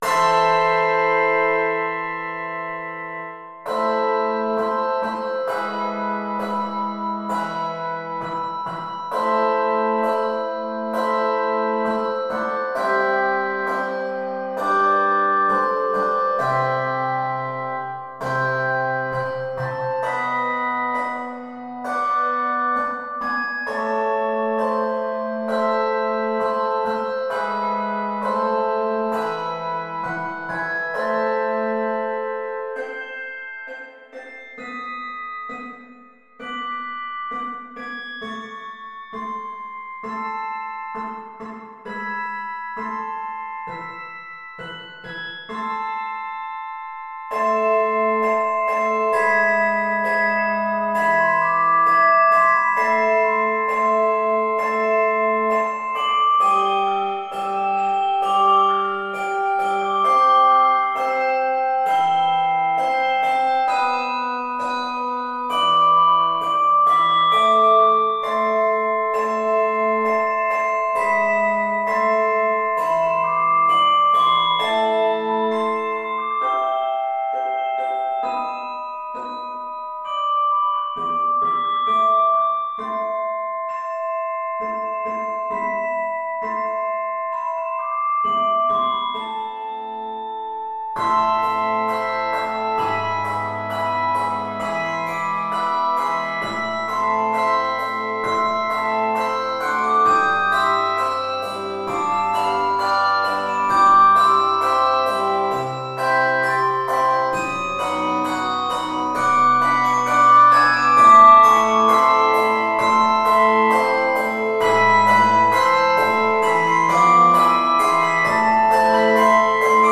for 5-Octave English Handbells